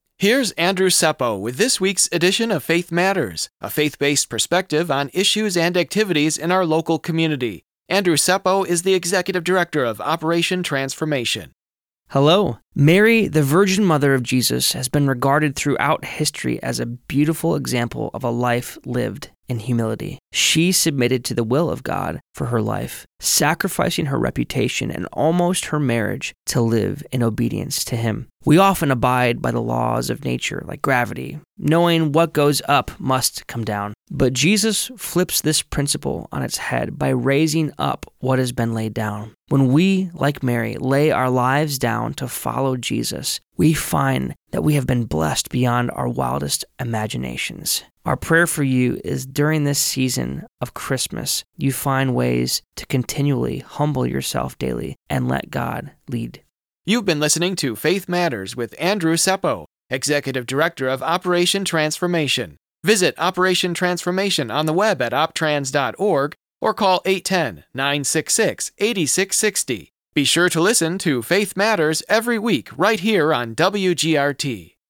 Faith Matters is a weekly radio feature that airs every Monday on WGRT 102.3 FM. Featuring information and commentary about spiritual issues - nationally and in your local community.